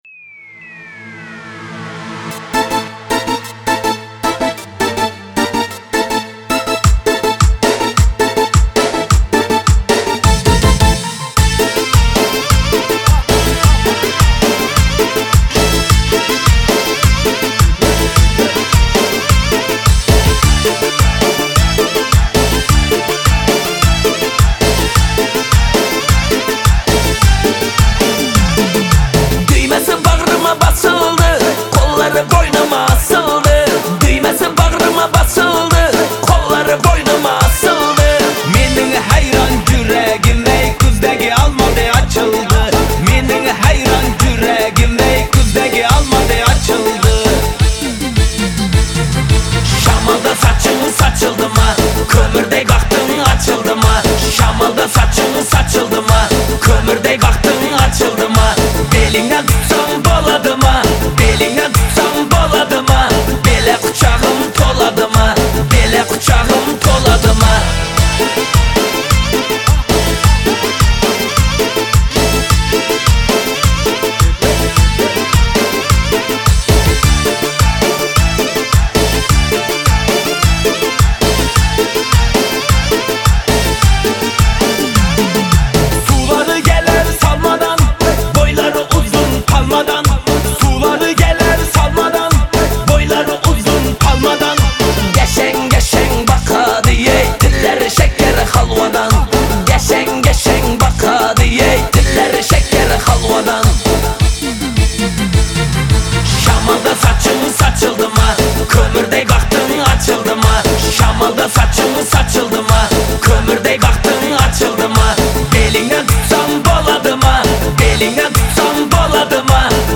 Трек размещён в разделе Узбекская музыка / Альтернатива.